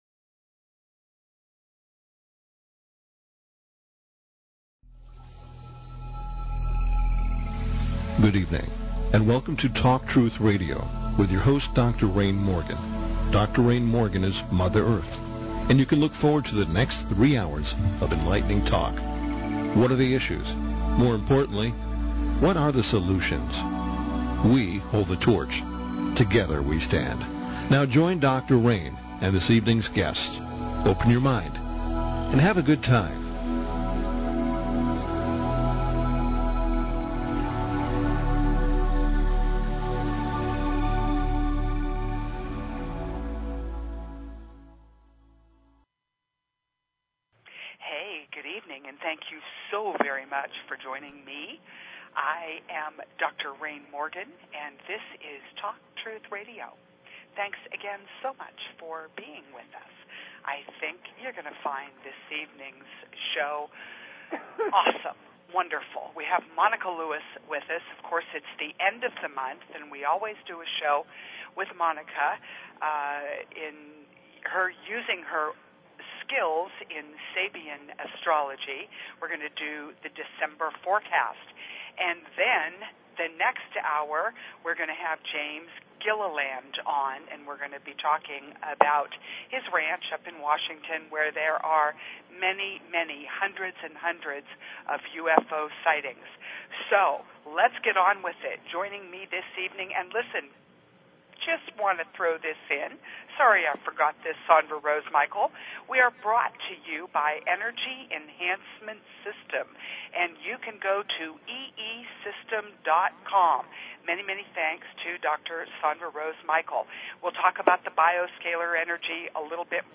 Talk Show Episode, Audio Podcast, Talk_Truth_Radio and Courtesy of BBS Radio on , show guests , about , categorized as